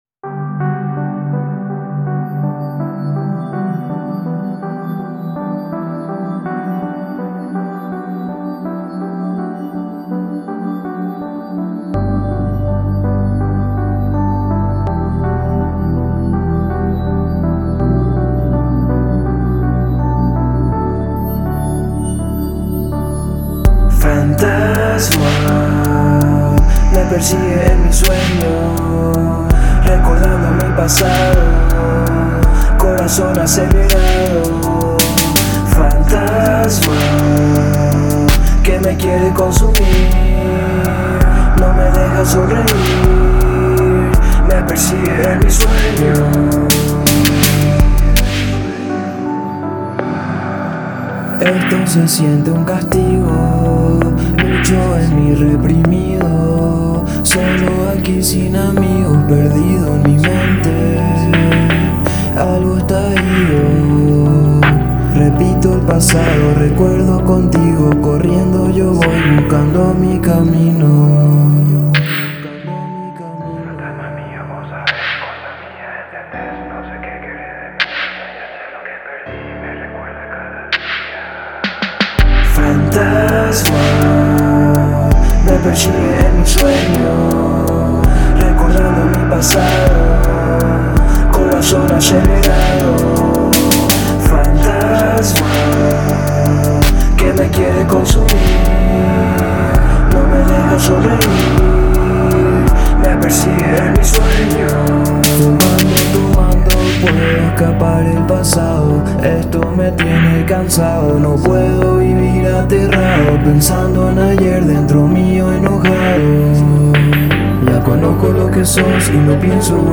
De melancólica melodía y de letras reflexivas